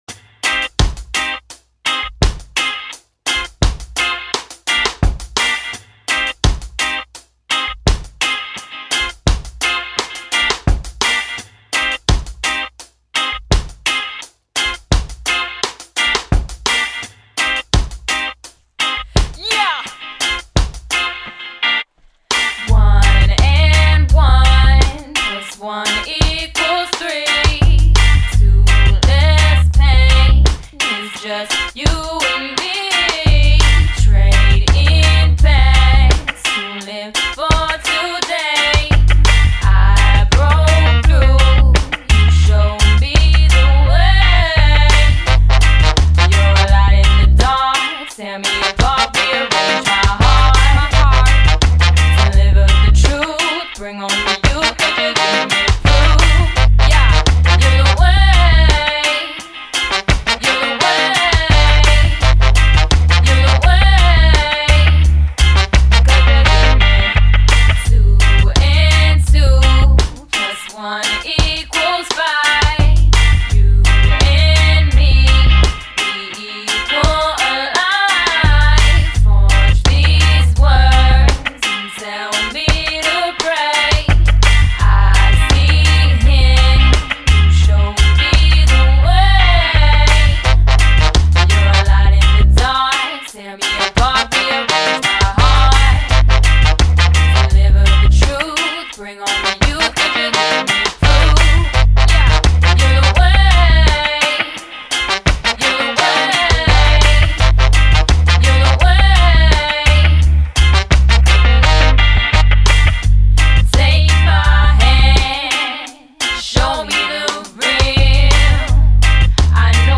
Guitar on tracks 5
Recorded at Ground Zero Studios